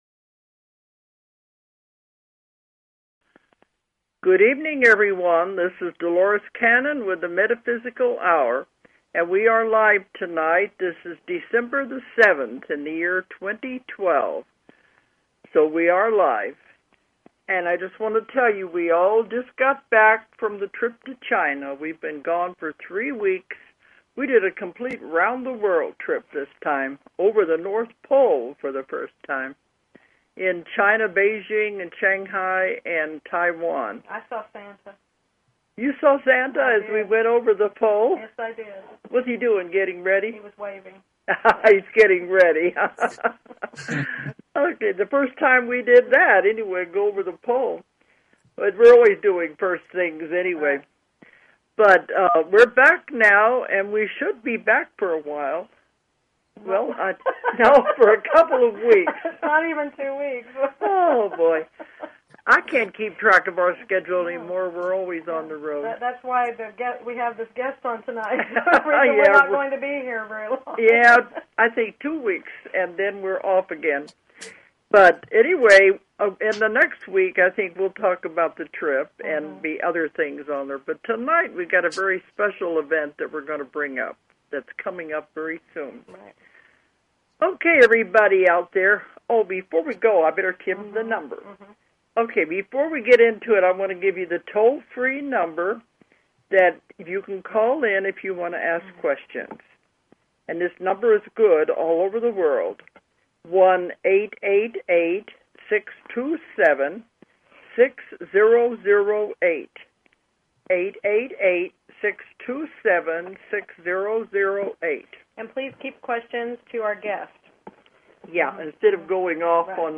Talk Show Episode, Audio Podcast, The_Metaphysical_Hour and Courtesy of BBS Radio on , show guests , about , categorized as